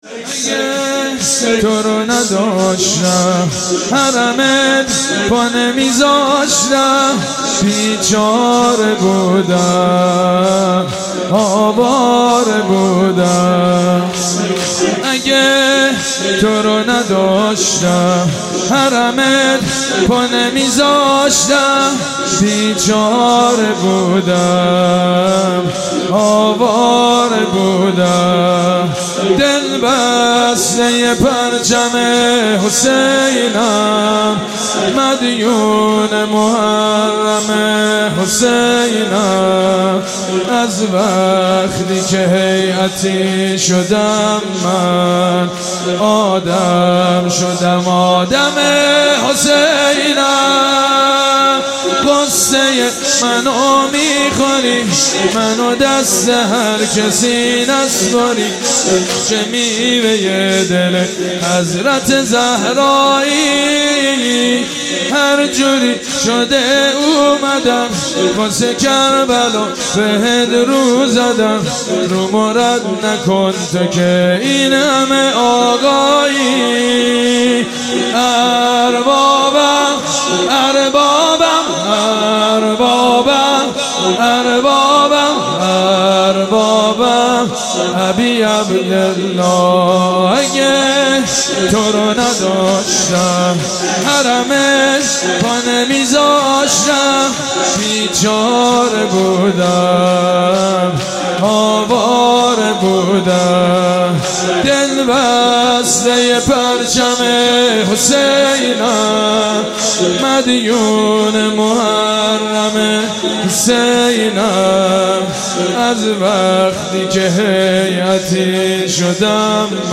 اشعار شهادت امام حسین با سبک شور از سید مجید بنی فاطمه -( اگه تو رو نداشتم حرمت پا نمیذاشتم )